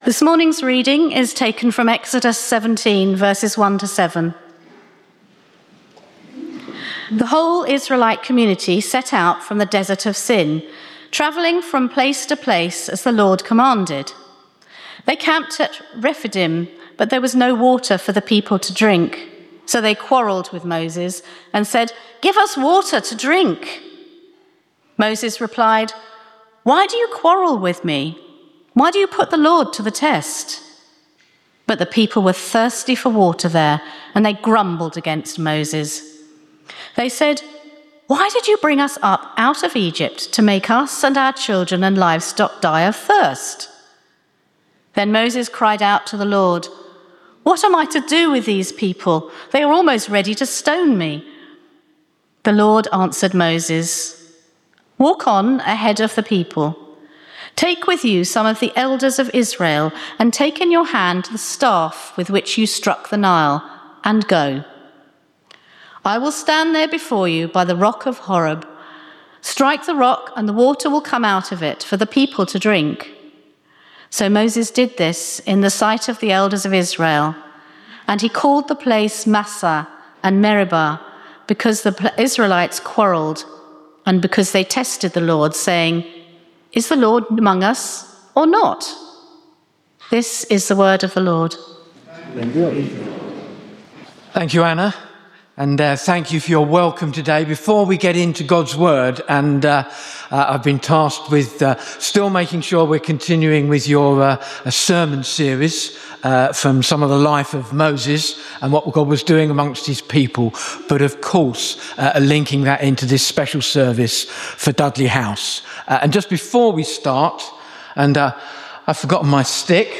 Informal Worship Speaker
Service Type: Informal Praise